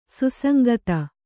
♪ susangata